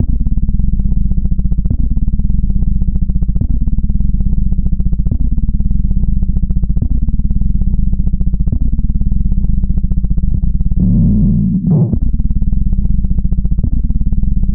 I would make a pattern using one track on the AR, then record it as a sample, then assign that sample to same track and repeat.